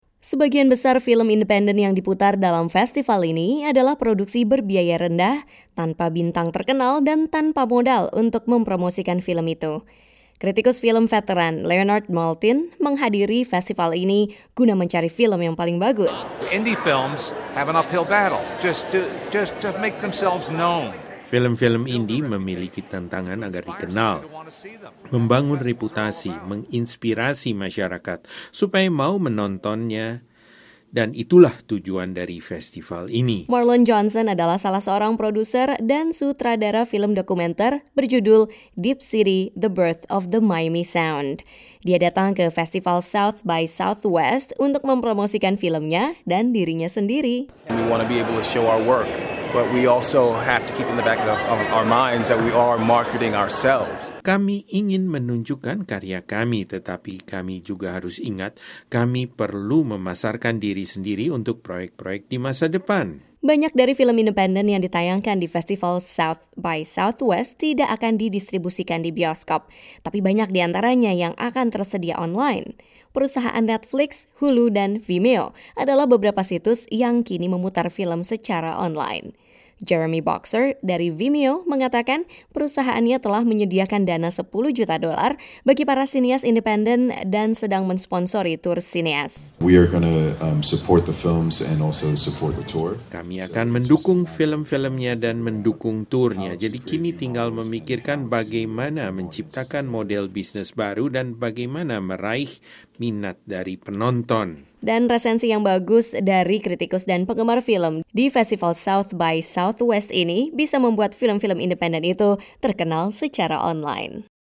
Festival South by Southwest di Austin, Texas, berakhir hari Minggu dengan penayangan film dan pertunjukkan musik. Ini merupakan ajang yang penting bagi sineas independen di seluruh dunia yang ingin merebut perhatian penonton serta ingin filmnya jadi pembicaraan di festival itu dan dimana saja. Wartawan VOA